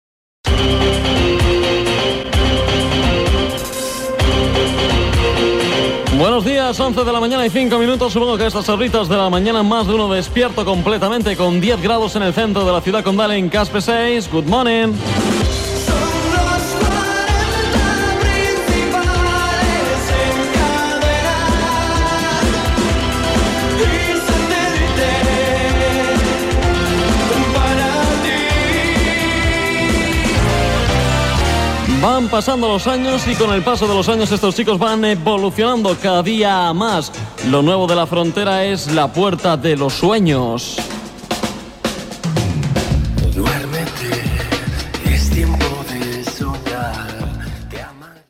Hora, temperatura, indicatiu de l'emissora i tema musical.
Musical
FM